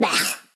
Expresión de repugnancia
exclamación expresión interjección repugnancia
Sonidos: Acciones humanas Sonidos: Voz humana